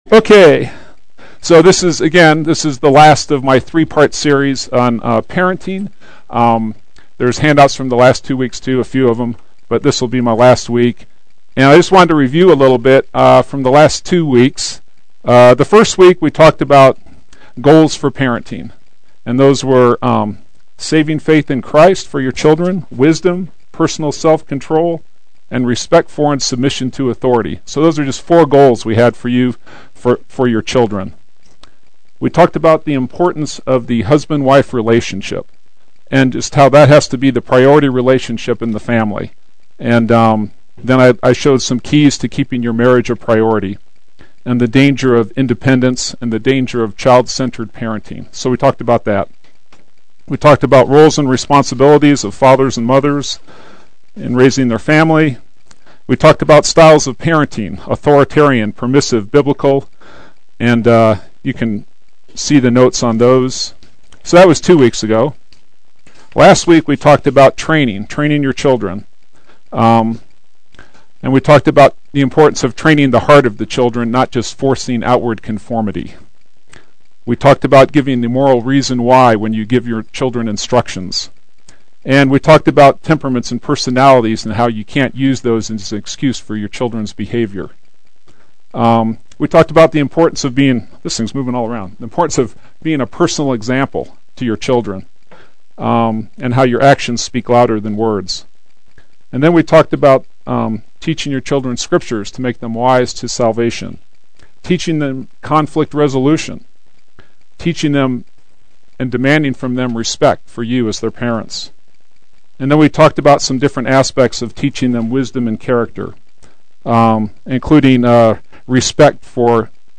Play Sermon Get HCF Teaching Automatically.
Parenting Adult Sunday School